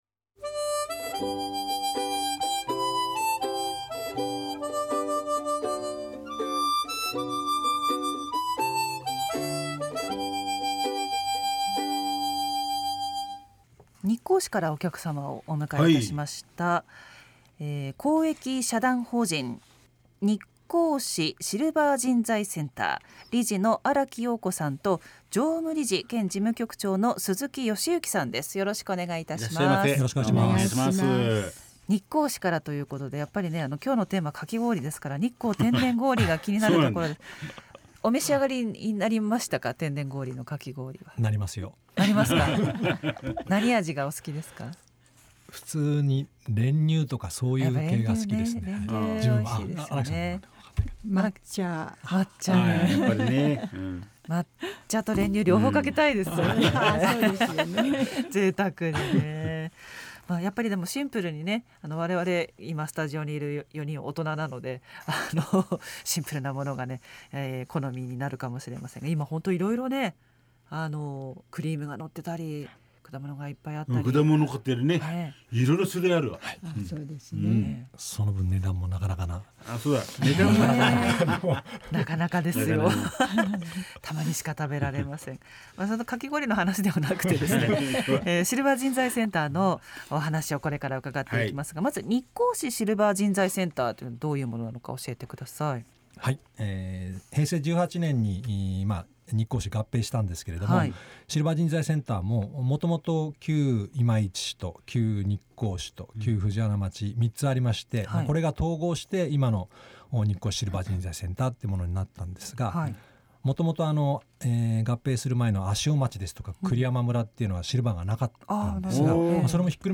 栃木放送ラジオに生出演しました!!